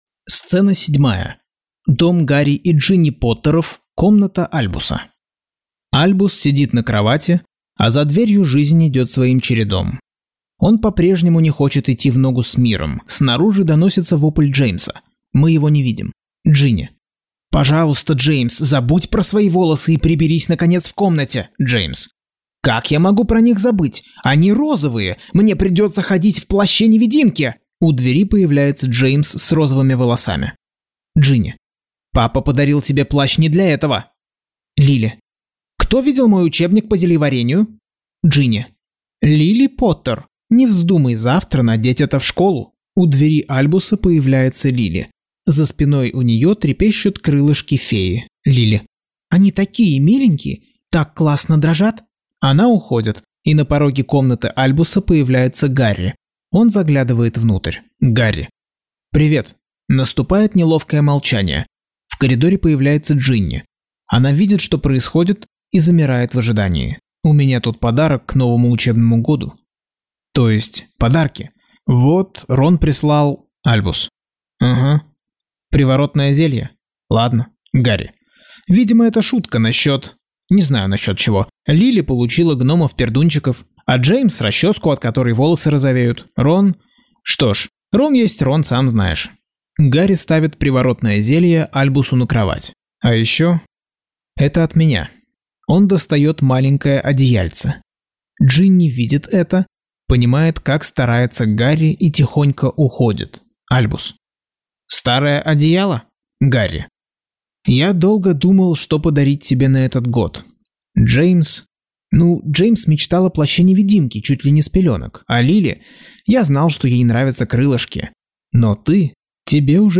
Аудиокнига Гарри Поттер и проклятое дитя.